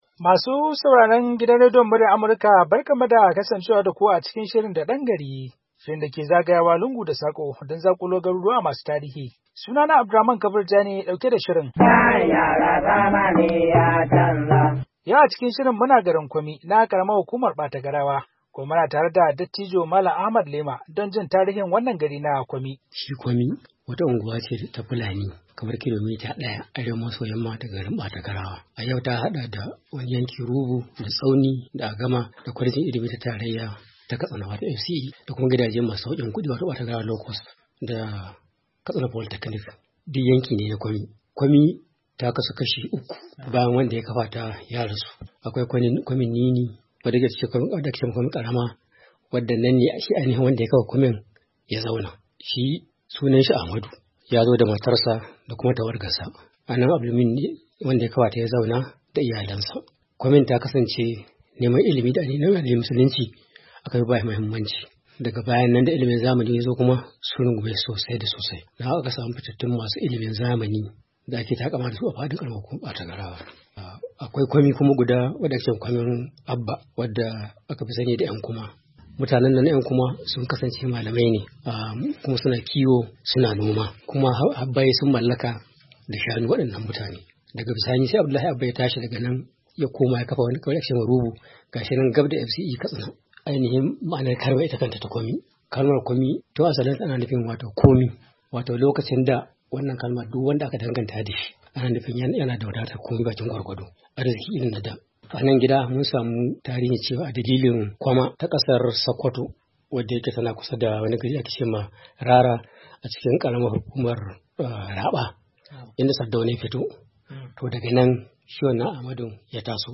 A wata hira